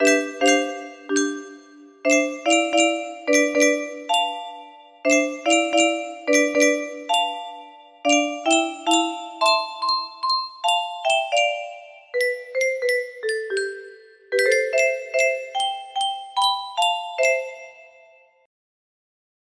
Unknown Artist - Untitled2 music box melody